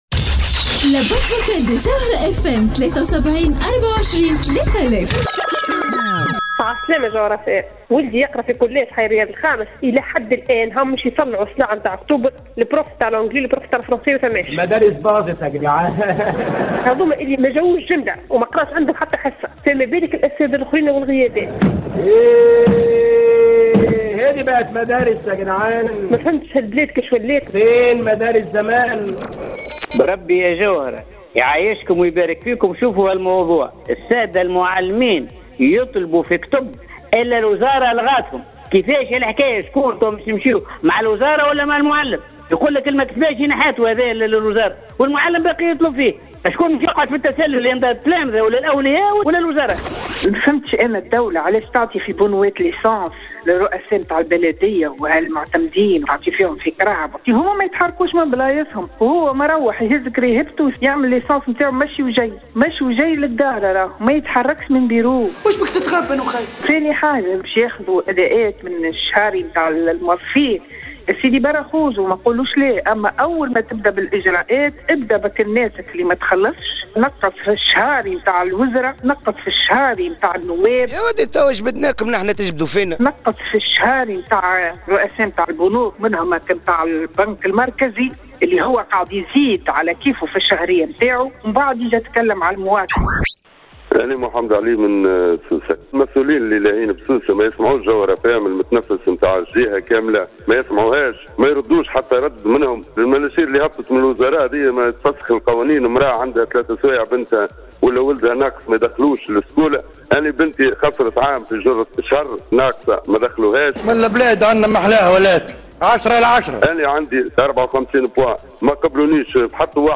مستمعة : الوشواشة قتلتنا بعد الأمطار